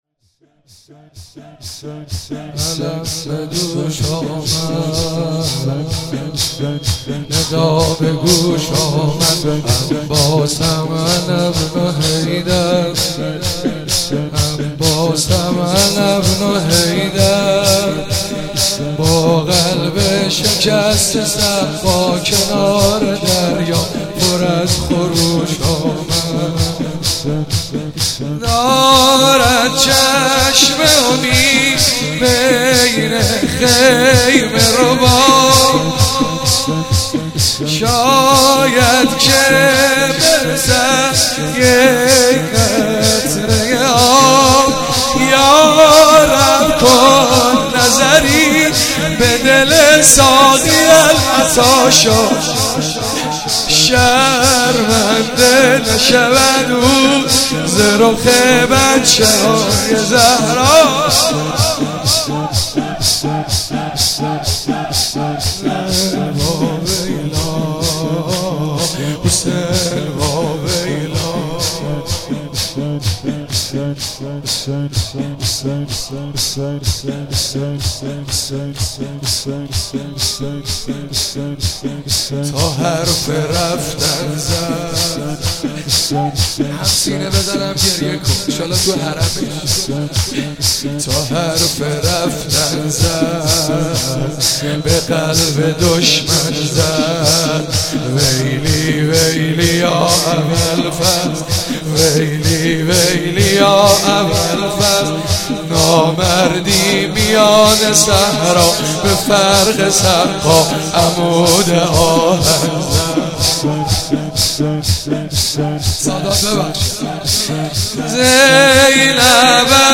چهاراه شهید شیرودی حسینیه حضرت زینب (سلام الله علیها)
شور- غوغا تو میدون چون